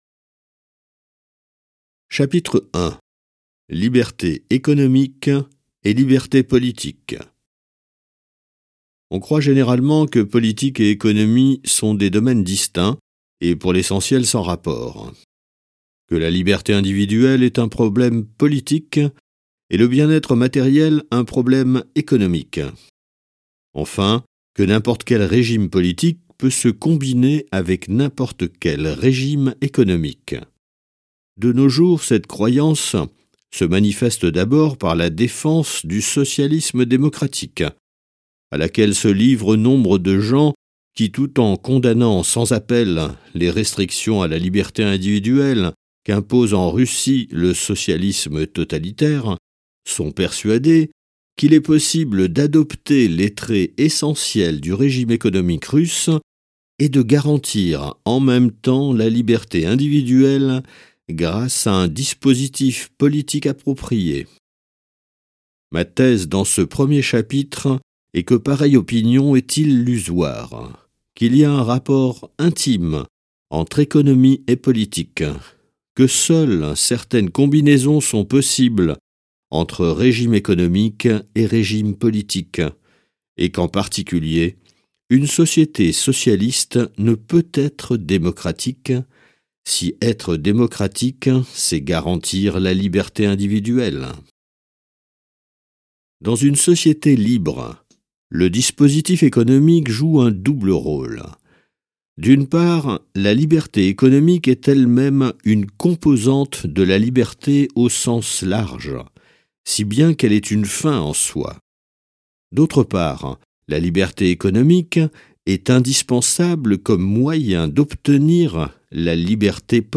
Livre audio: Capitalisme et liberté de Milton Friedman